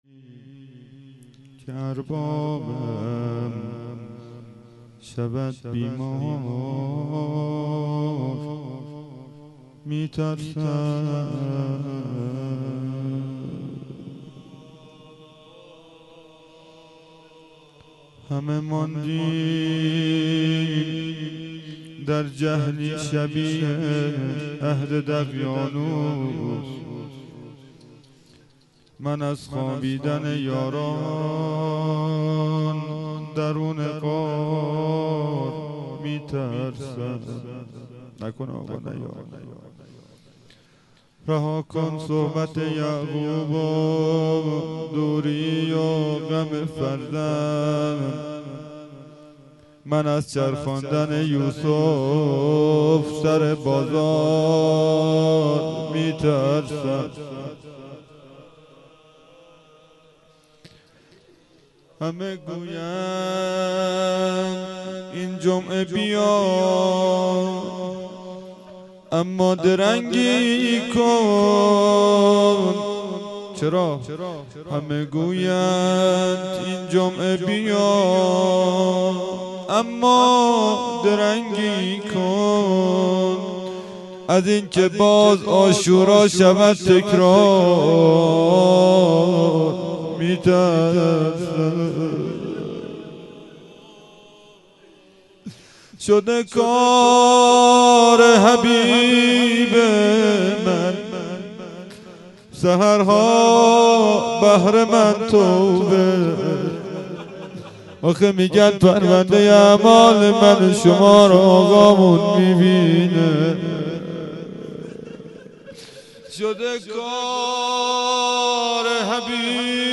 روضه و مناجات